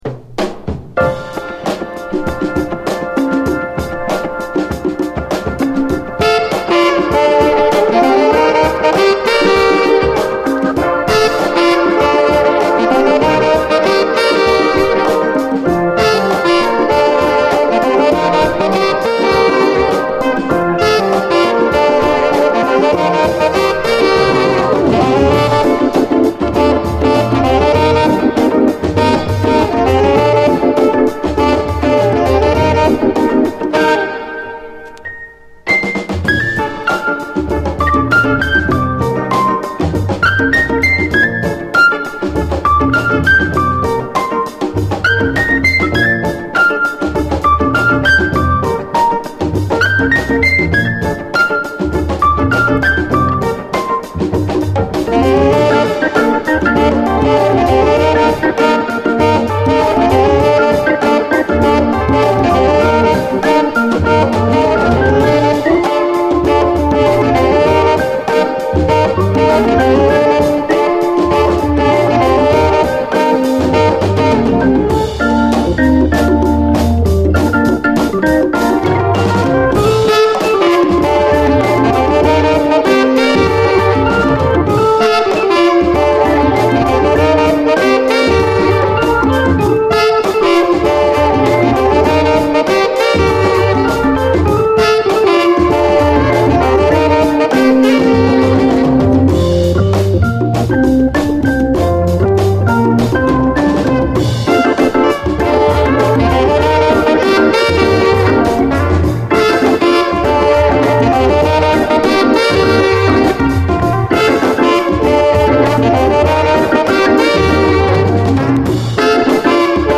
タダのB級ディスコ盤ではありません！